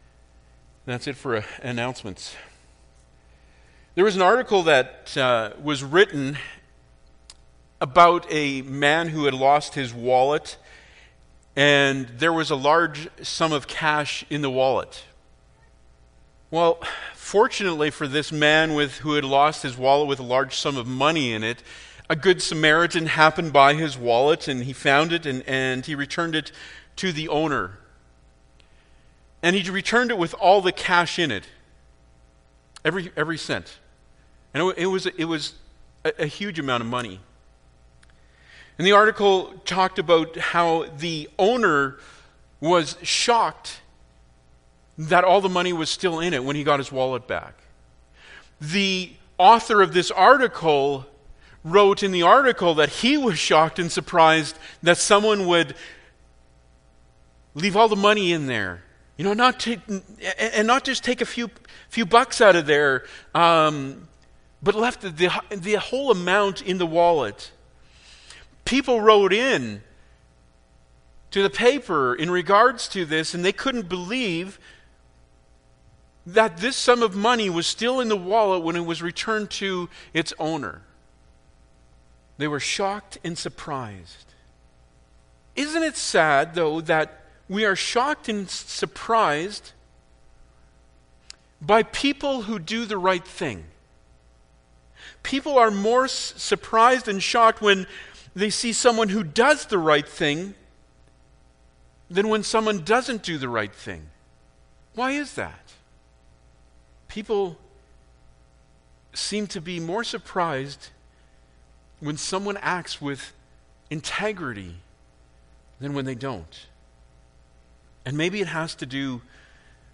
Bible Text: Ephesians 4:1 | Preacher